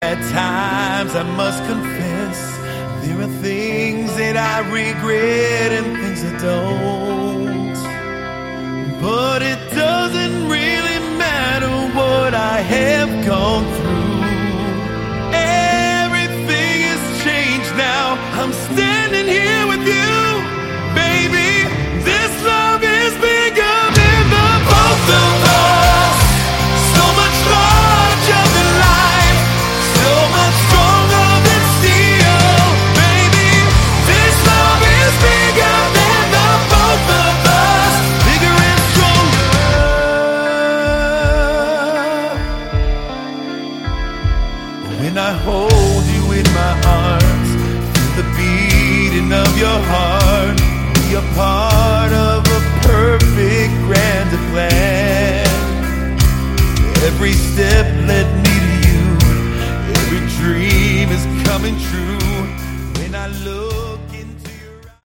Category: Melodic Rock
lead vocals, keyboards
rhythm and lead guitars, backing and lead vocals
drums
bass
bonus studio track